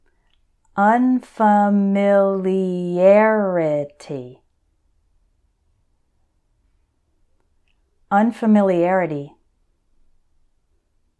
So for these words I’ll say them once slowly and once normally, so you can repeat both times.
un – fa – mil – i – AR – i – ty………. unfamiliarity